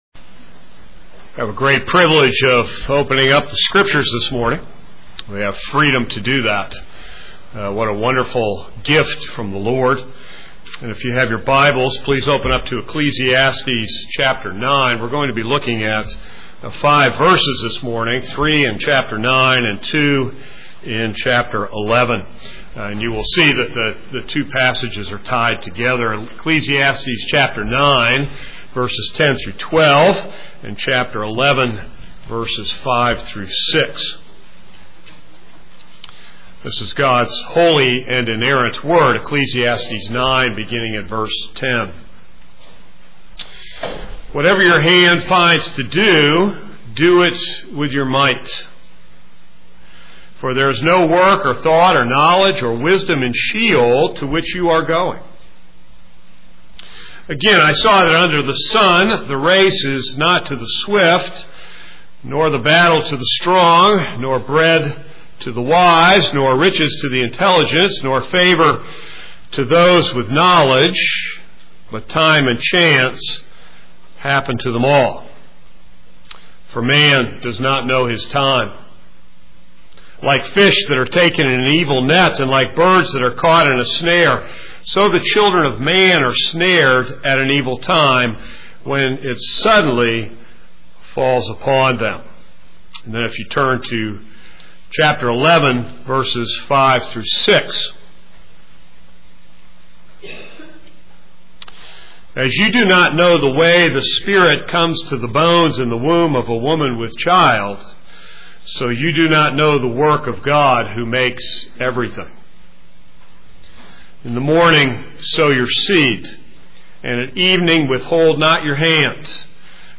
This sermon is based on Ecclesiastes 9:10-12 and Ecclesiastes 11:5-6.